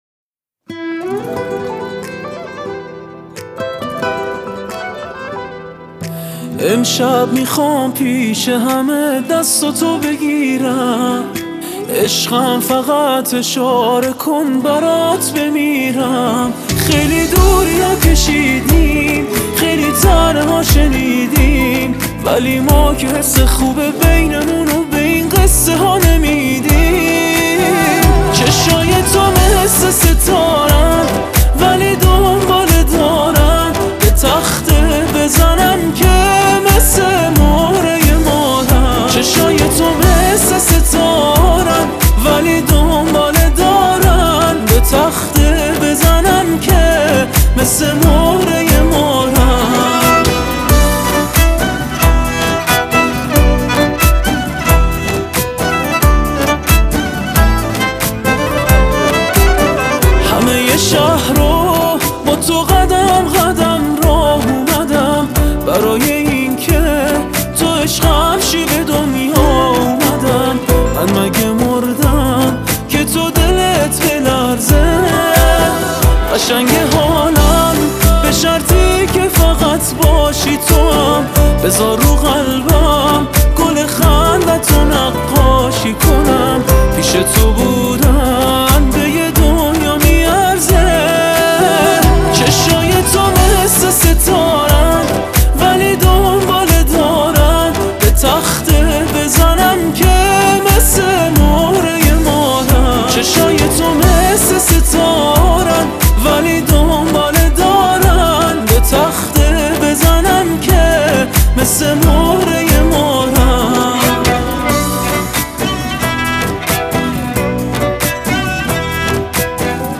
پاپ